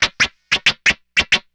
SCRAPEAGE 1.wav